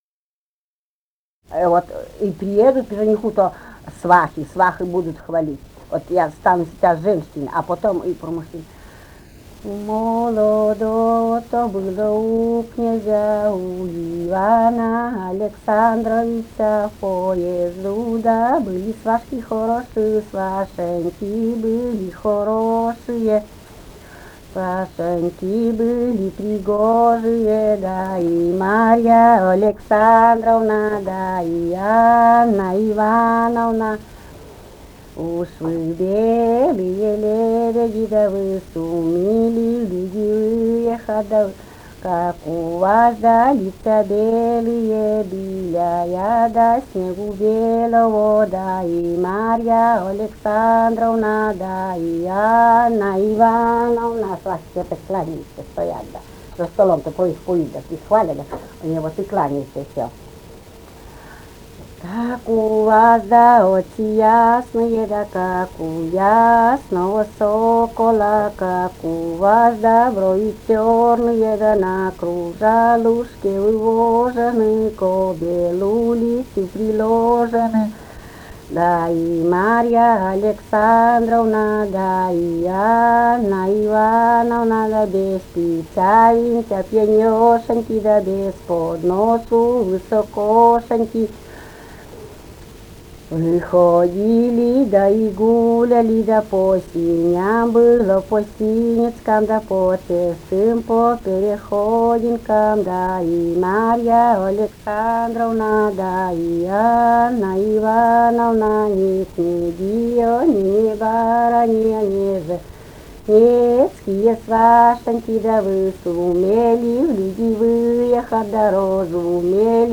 Живые голоса прошлого 005. У молодово-то было у князя» (свадебная).